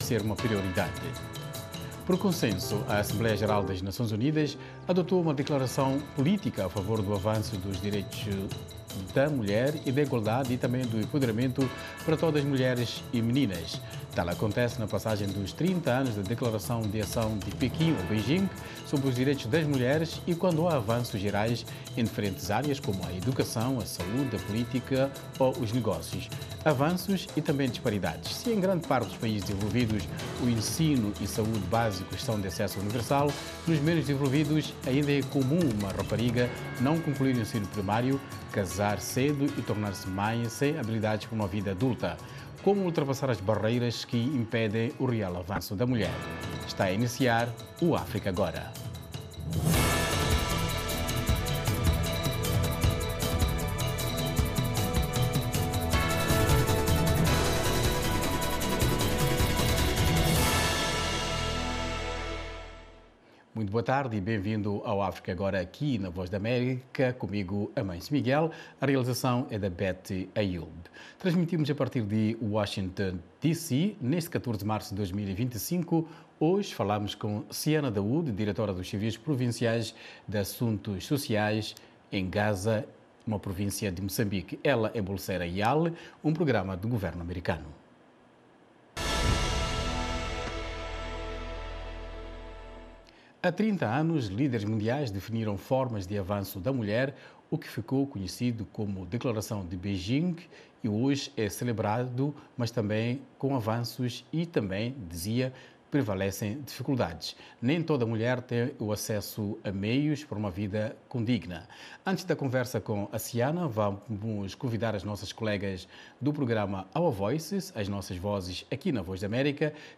África Agora, o espaço que dá voz às suas preocupações. Especialistas convidados da VOA irão comentar... com a moderação da Voz da América. Um debate sobre temas atuais da África lusófona.